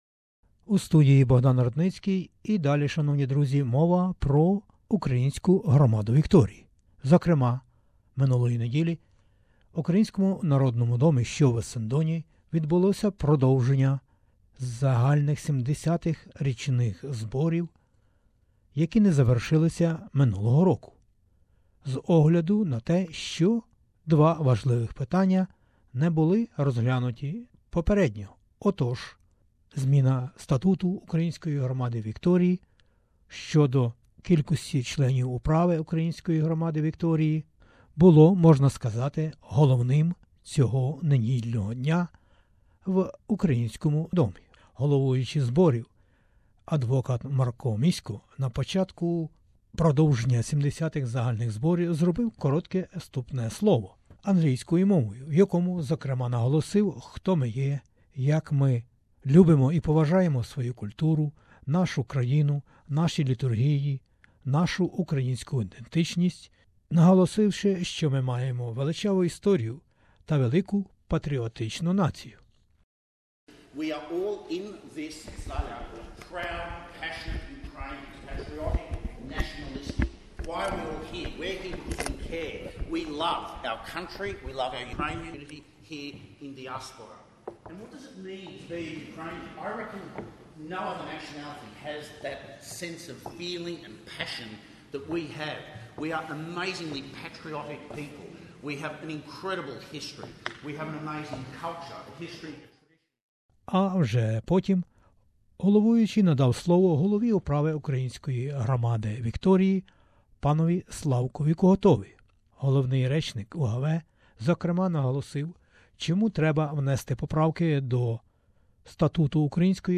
REPORT FROM THE ANNUAL GENERAL MEETING OF THE ASSOCIATION OF UKRAINIANS IN VICTORIA (AUV), Ukrainian House, Essendon, 18 February 2018.